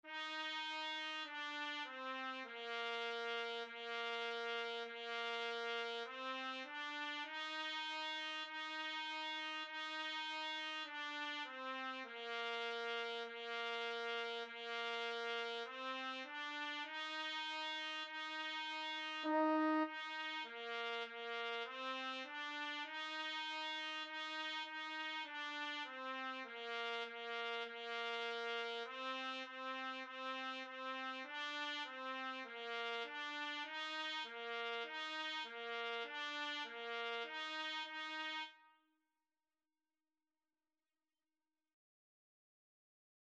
Bb4-Eb5
4/4 (View more 4/4 Music)
Trumpet  (View more Beginners Trumpet Music)
Classical (View more Classical Trumpet Music)